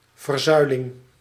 Pillarisation (a calque from the Dutch: verzuiling [vɛrˈzœylɪŋ]